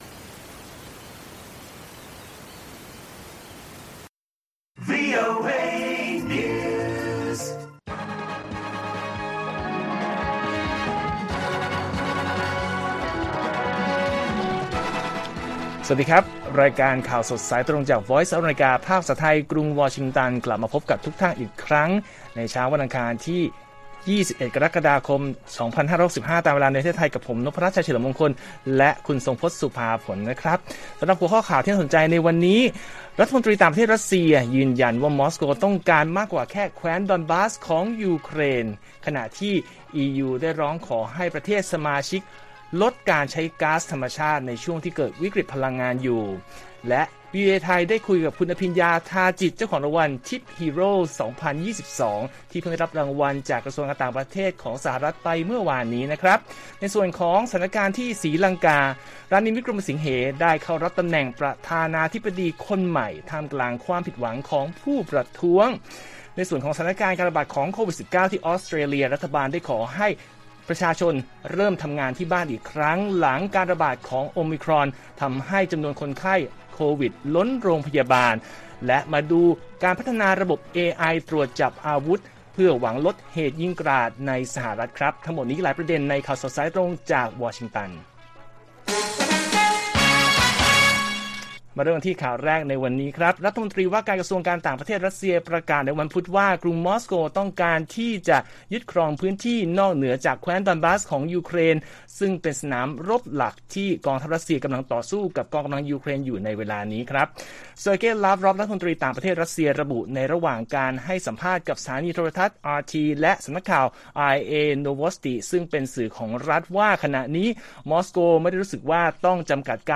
ข่าวสดสายตรงจากวีโอเอไทย 6:30 – 7:00 น. วันที่ 20 ก.ค. 65